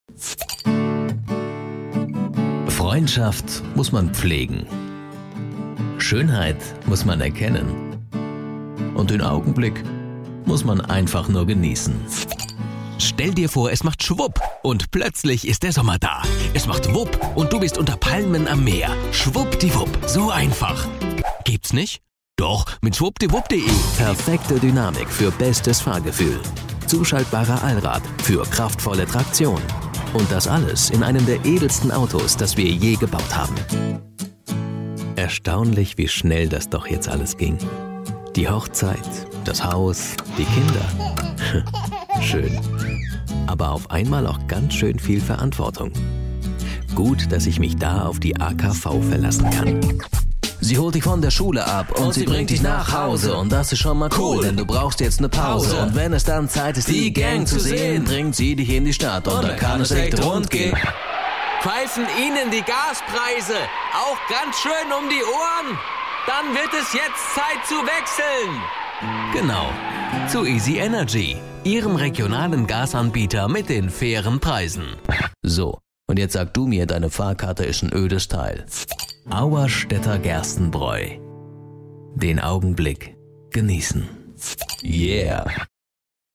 Studioausstattung: Desone/Soundblocker Kabine, Neumann TLM 103, Gefell M930, UA LA-610 Vollröhrenpreamp, GAP pre-73 MKII, RME Fireface, Genelec, Musiktaxi
Freundlich verbindliche Stimme.
Sprechprobe: Werbung (Muttersprache):